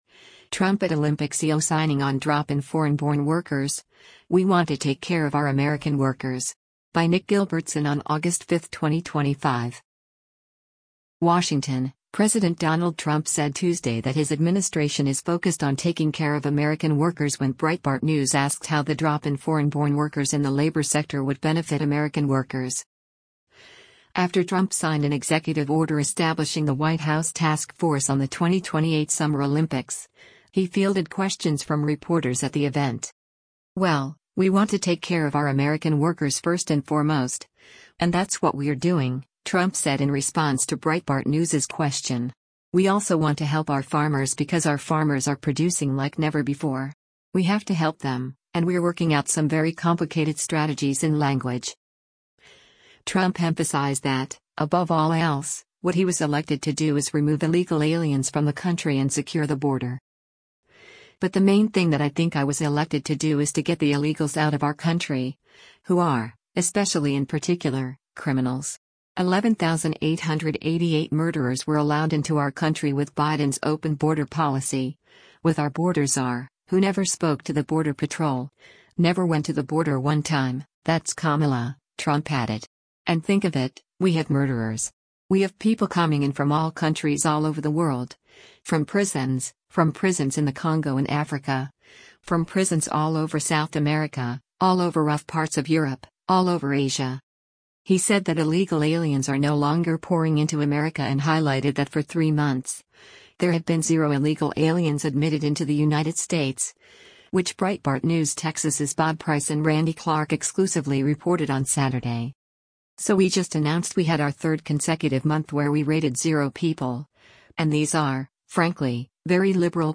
Trump at Olympics EO Signing on Drop in Foreign-Born Workers: ‘We Want to Take Care of Our American Workers’
After Trump signed an executive order establishing the White House Task Force on the 2028 Summer Olympics, he fielded questions from reporters at the event.